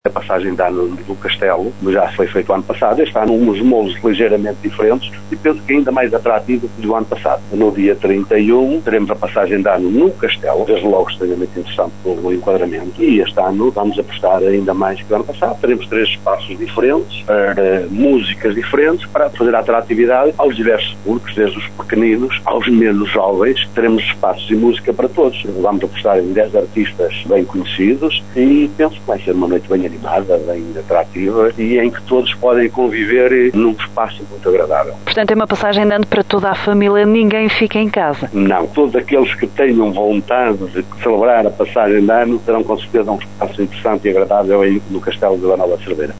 É o caso de Vila Nova de Cerveira que, pelo segundo ano consecutivo, vai organizar uma festa de passagem de ano no Castelo com música para toda a família, como faz questão de sublinhar o autarca local, Fernando Nogueira.